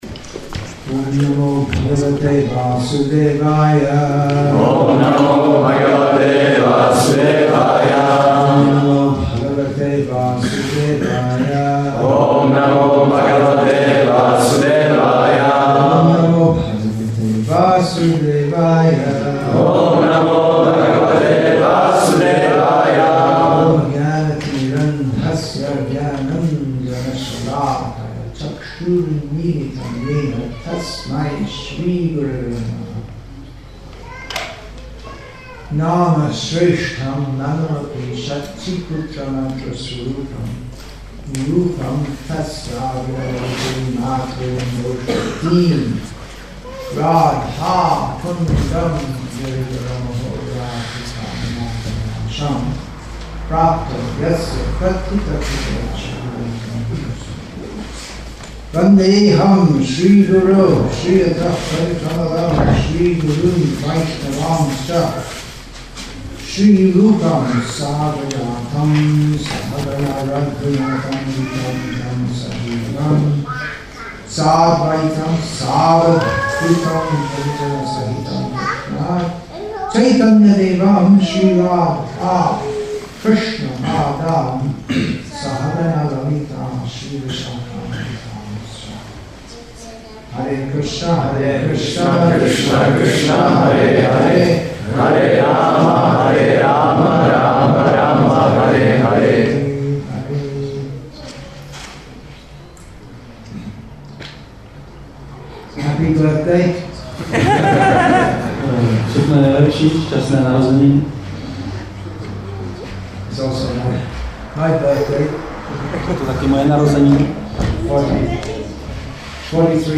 Initiation Lecture